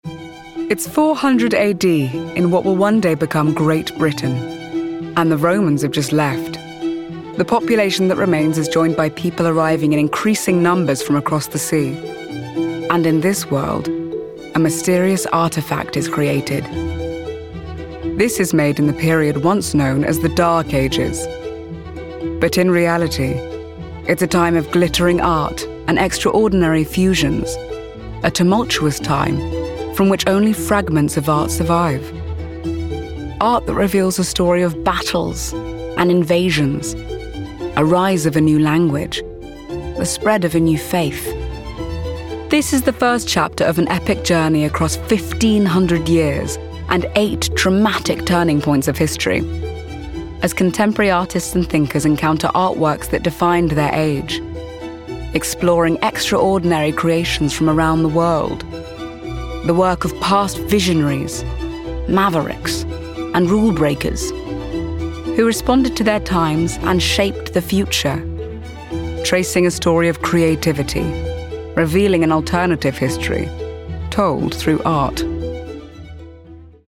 RP
Female
Husky
Warm
THE ART THAT MADE US DOCUMENTARY